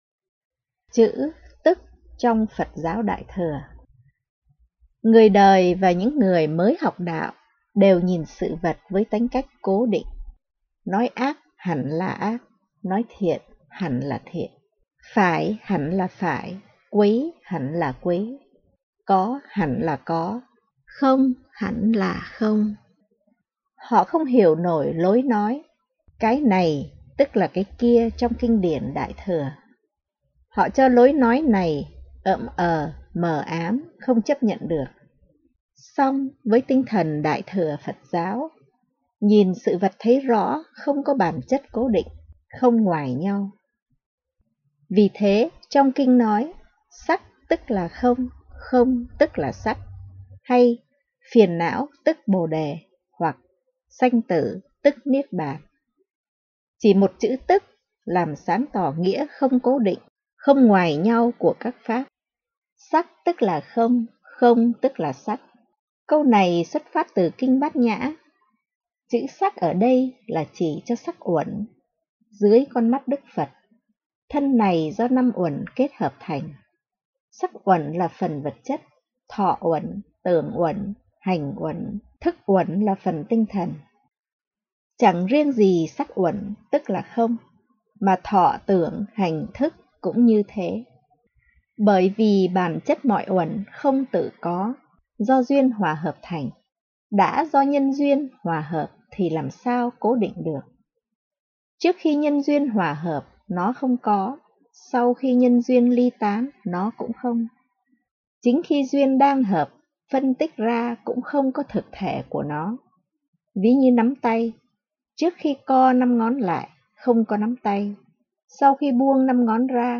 Audio Book Bước Đầu Học Phật